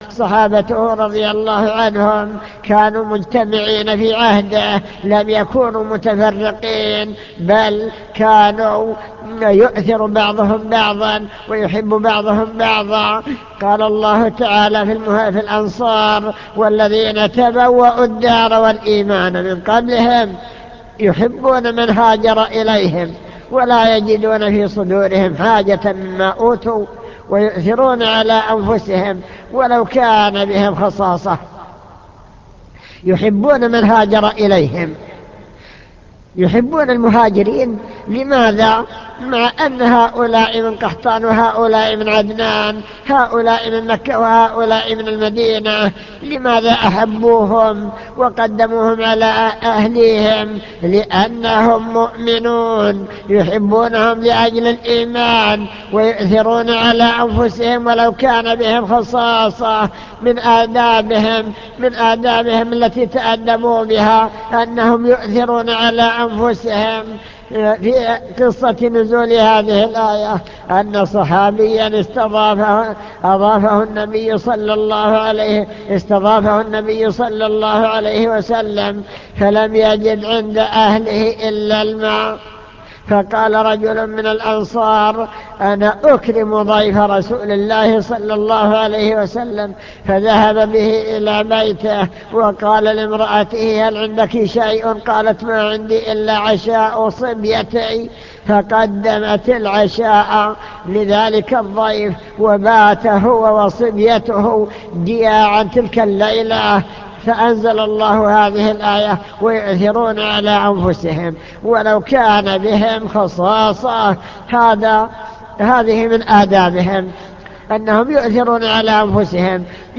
المكتبة الصوتية  تسجيلات - محاضرات ودروس  درس الآداب والأخلاق الشرعية